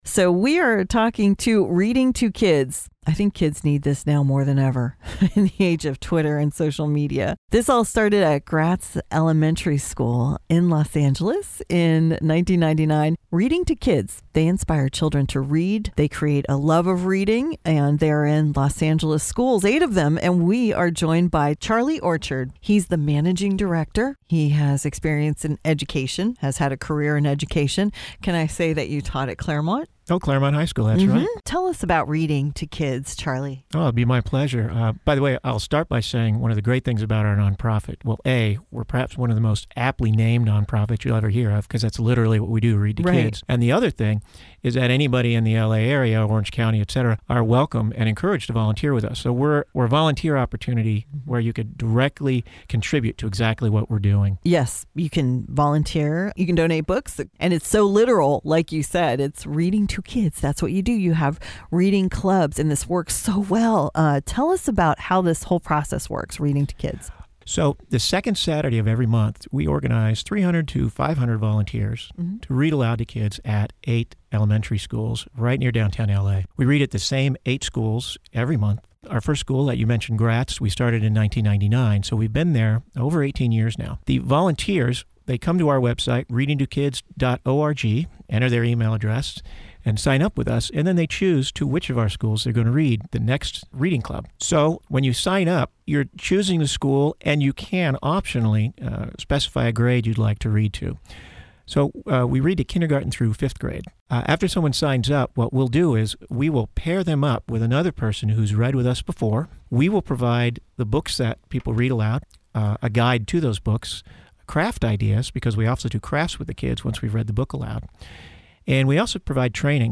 Originally broadcast December 4, 2016 on FM 100.3 The Sound